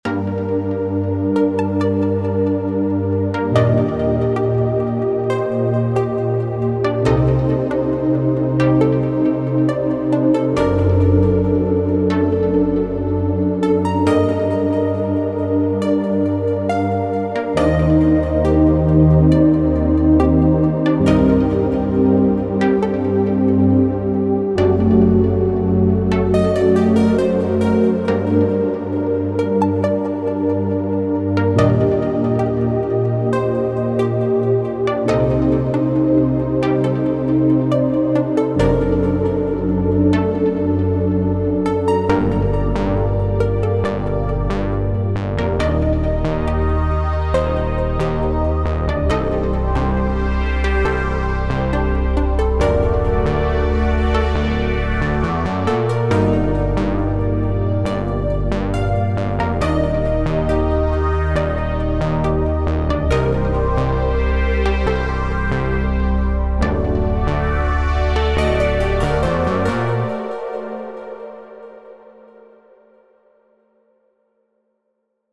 Демка на оценку
в первую половину этот пресет вставил- он прикольный - ненавязчивый, на заднем плане дает пространство, но не выпирает вперед других инструментов, спасибо!